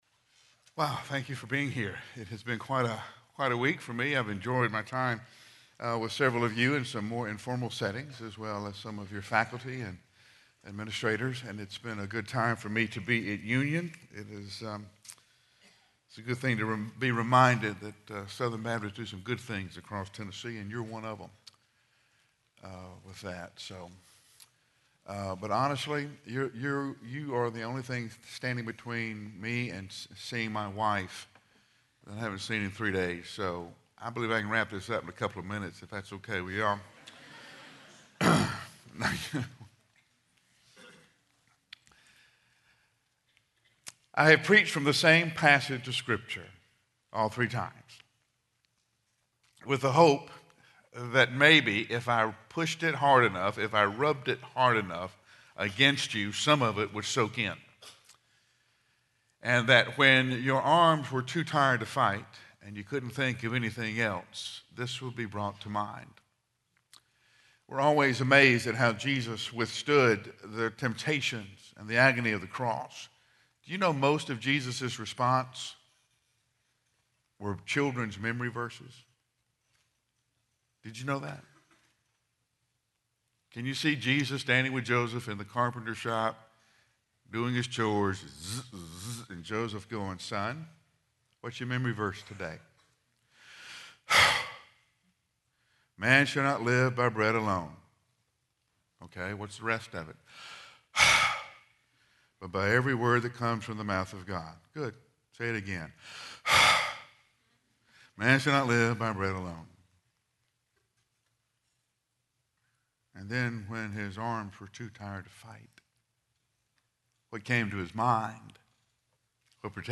Address: "Love Yourself" Recording Date: Oct 3, 2014, 10:00 a.m. Length: 25:28 Format(s): MP3 ; Listen Now Chapels Podcast Subscribe via XML